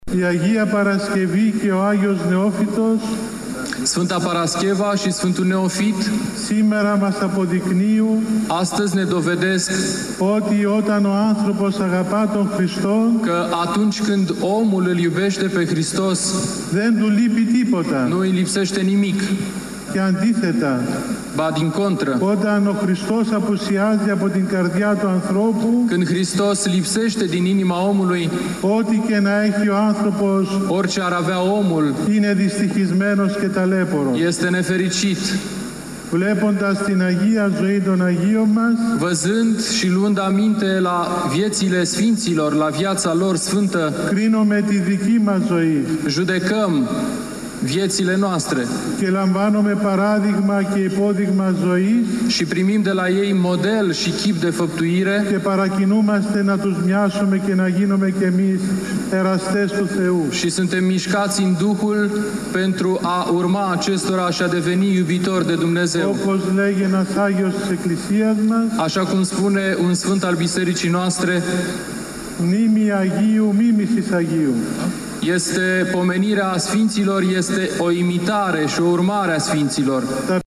UPDATE ora 12:30 Peste 5000 de credincioşi veniţi la Iaşi din toată ţara şi din străinătate pentru a lua parte la hramul Sfintei Cuvioase Parascheva, au participat la slujba Sfintei Liturghii oficiată pe o scenă special amenajată la intrarea în curtea Mitropoliei Moldovei şi Bucovinei.
Cuvântul de învățătură a fost rostit de IPS Atanasie: